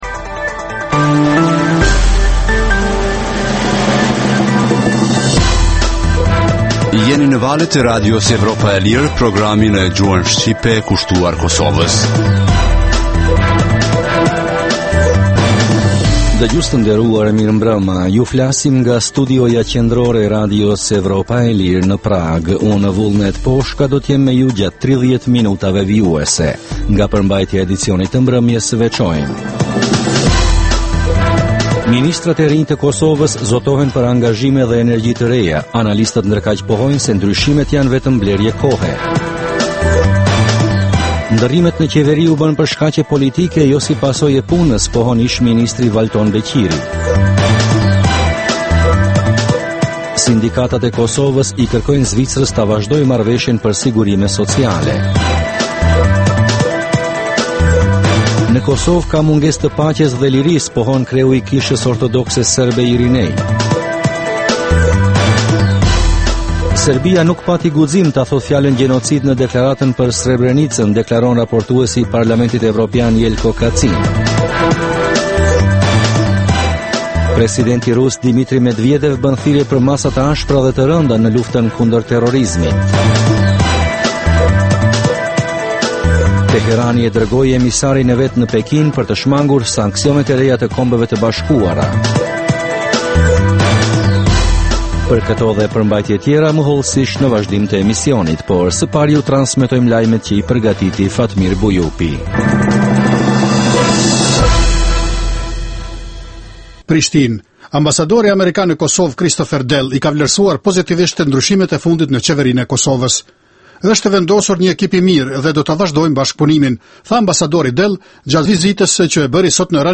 Emisioni i orës 21:00 është rrumbullaksim i zhvillimeve ditore në Kosovë, rajon dhe botë. Rëndom fillon me buletinin e lajmeve dhe vazhdin me kronikat për zhvillimet kryesore politike të ditës. Në këtë edicion sjellim intervista me analistë vendor dhe ndërkombëtar për zhvillimet në Kosovë, por edhe kronika dhe tema aktuale dhe pasqyren e shtypit ndërkombëtar.